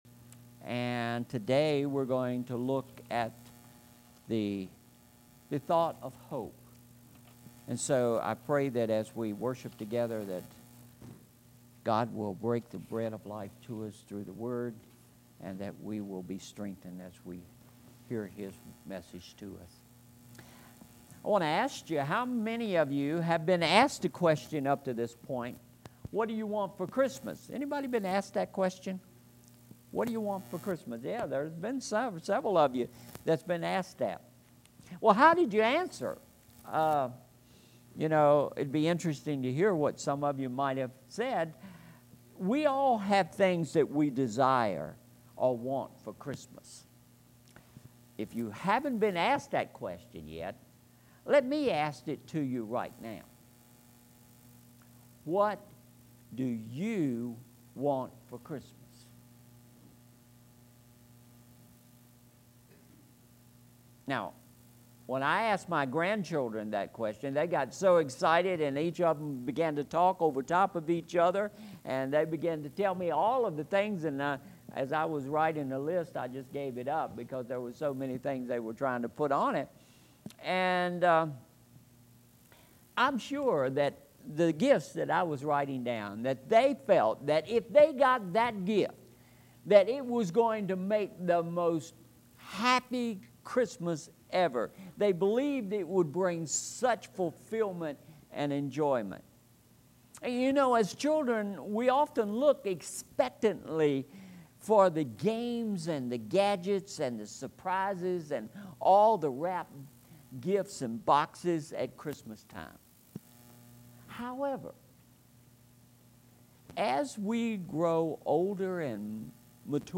Sermon Series: All I Want for Christmas – Part 1 Message Aim: First Sunday of Advent Sermon Title: “Hope” Scripture: Luke 1:26b-33 6b God sent the angel Gabriel to Nazareth, a town in Gali…